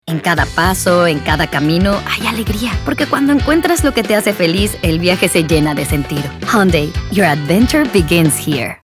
Commercial
Warm - Friendly